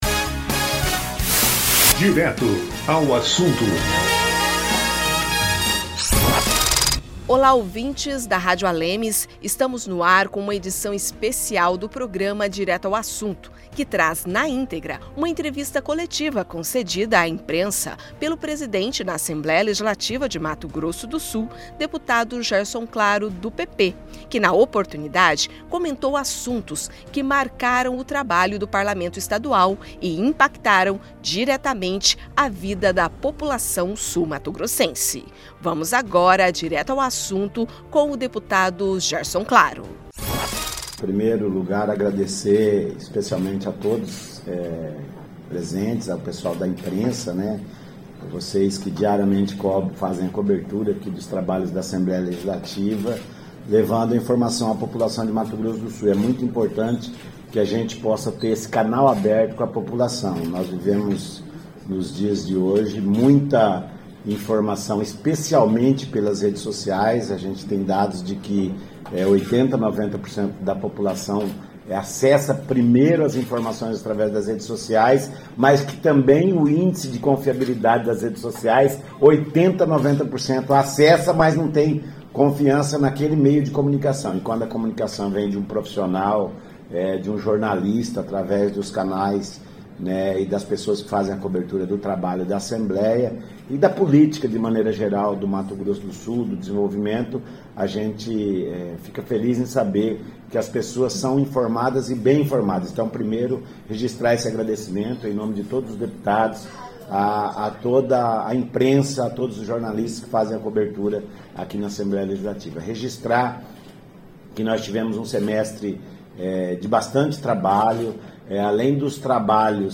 Durante coletiva à imprensa, nesta quinta-feira (13), o presidente da Assembleia Legislativa de Mato Grosso do Sul (ALEMS), deputado Gerson Claro (PP), fez um balanço do trabalho desenvolvido pelo Poder Legislativo no primeiro semestre de 2023, que impactaram diretamente a vida população sul-mato-grossense.